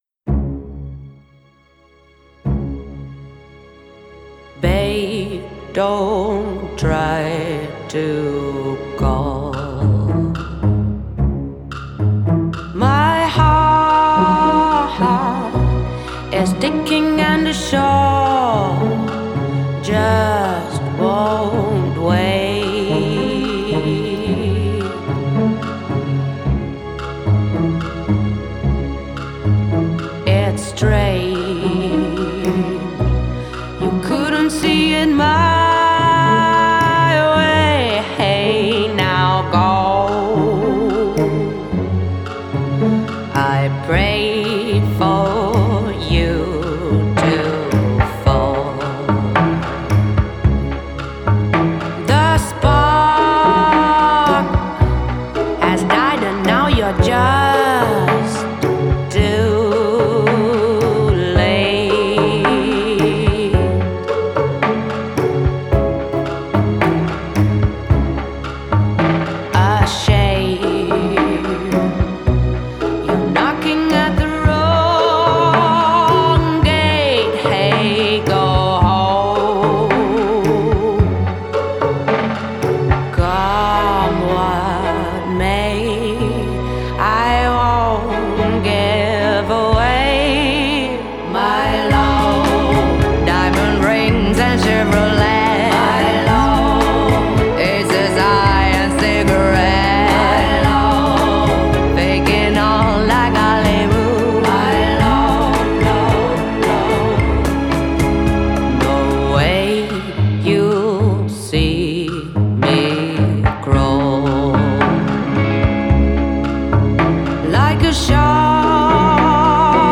Genre: Indie Pop, Soul, Jazz